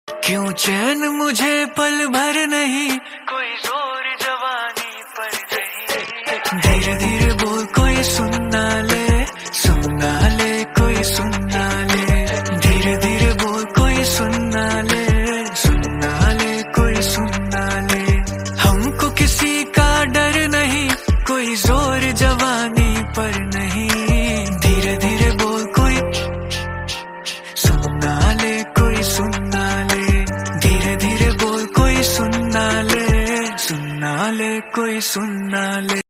Remix Ringtone